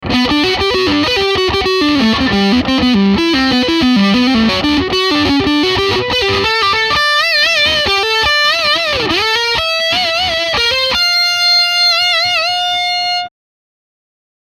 High-Gain im Dirty-Channel
Und da macht auch der hier getestete Orange TH30C keine Ausnahme: Der Fokus liegt eindeutig im High-Gain-Bereich.
Die Gainreserven sind beachtlich und reichen vom crunchigen AC/DC-Rhythm-Sound bis hin zum Death-Metal-Brett.
Der verzerrte Orange TH30C
orange_th30c-bk_testbericht_dirty_2.mp3